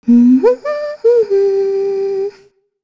Shea-Vox_Hum_kr.wav